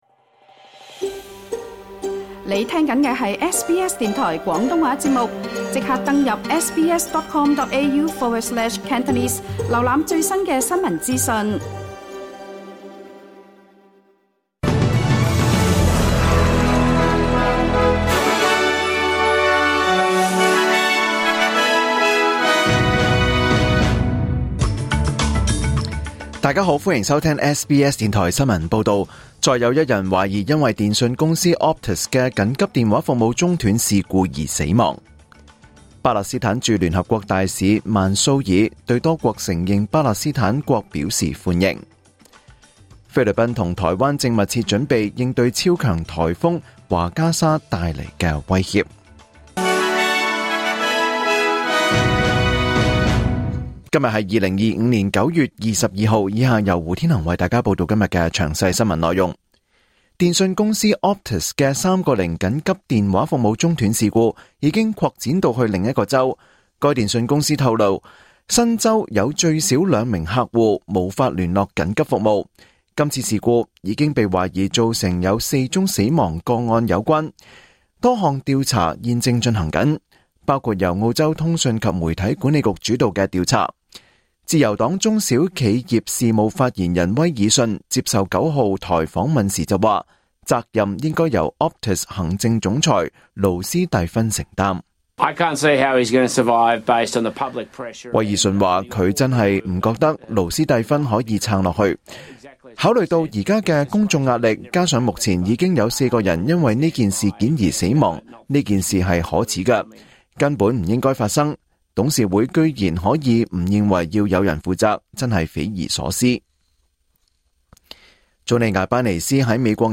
2025 年 9 月 22 日 SBS 廣東話節目詳盡早晨新聞報道。